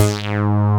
SYN_StBas F4#.wav